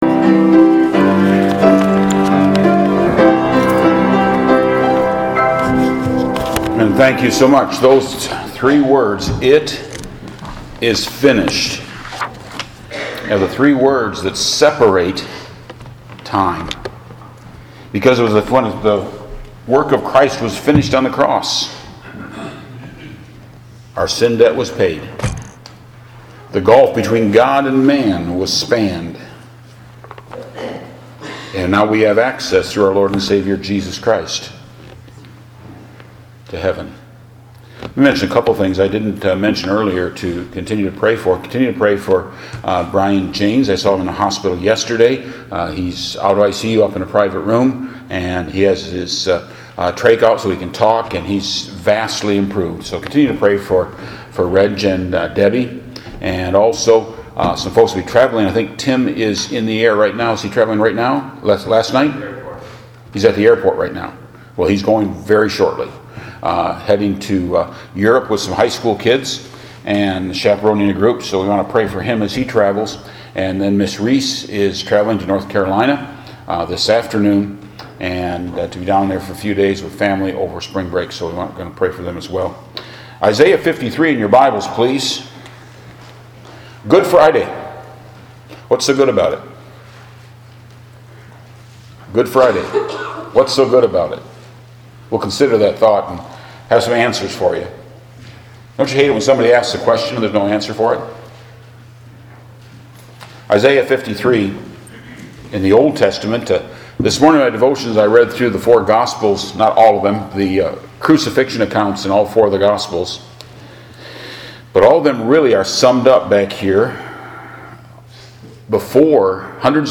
Good Friday Service | First Baptist Church
Sermon MP3